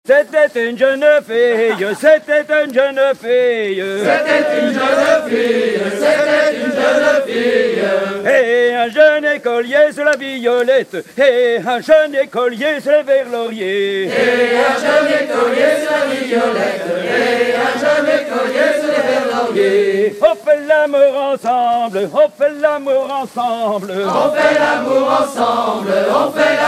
gestuel : à marcher
Genre laisse
Pièce musicale éditée